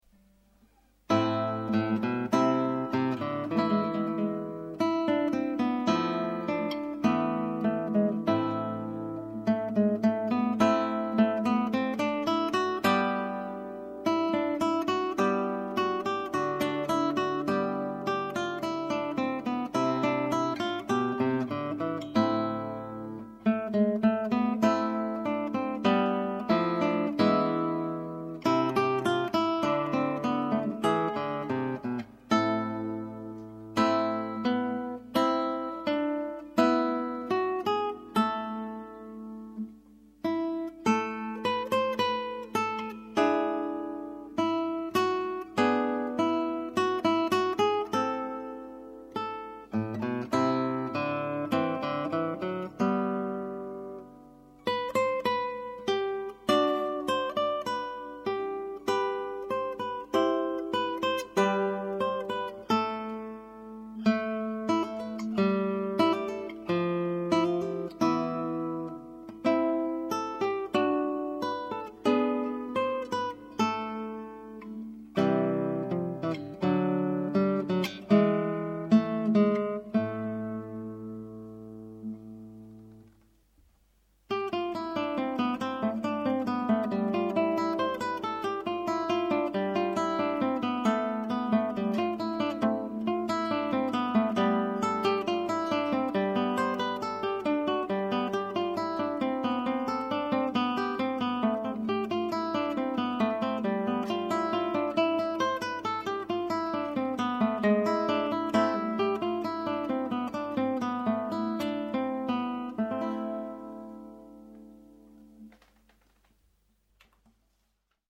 De Pavane is een statige hofdans in een tweedelige maat.